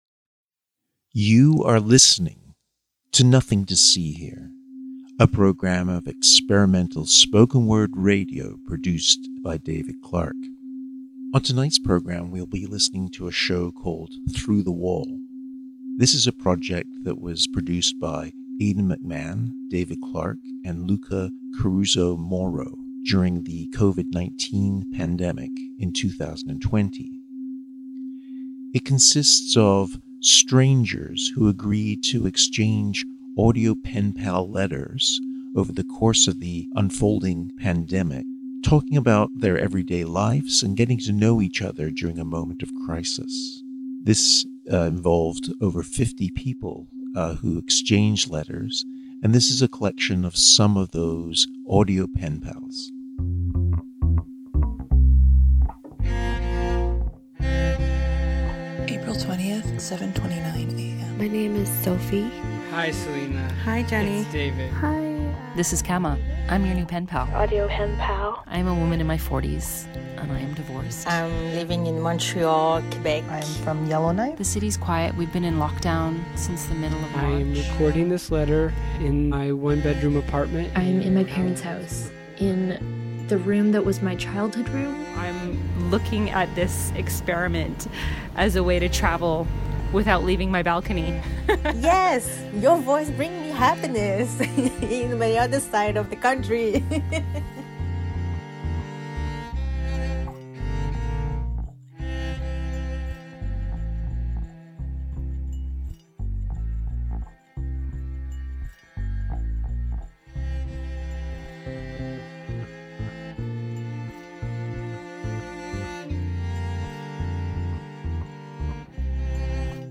During the first months of the COVID-19 pandemic, 50 strangers exchanged weekly audio letters. From across the country, these audio pen pals bonded and explored the joys and fears of our changing world. Equal parts oral history, community project, and audio poem, Through the Wall captured this time of extreme flux from the day to day perspective of regular people